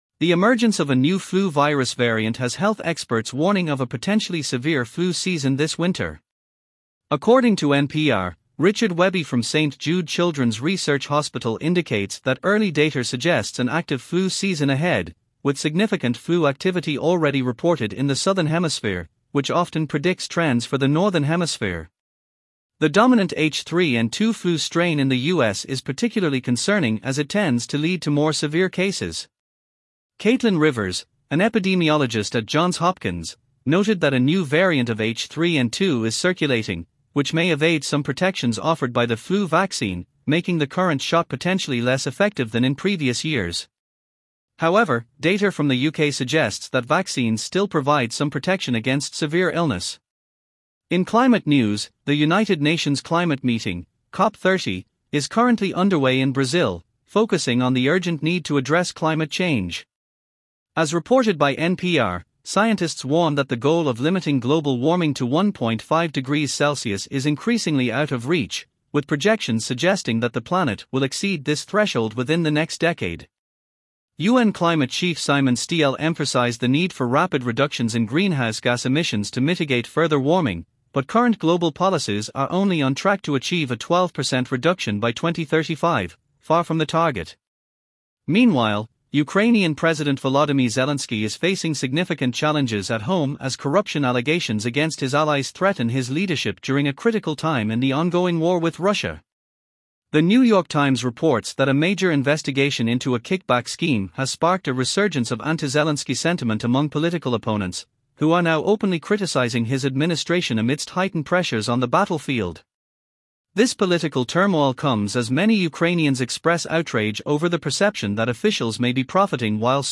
Top News Summary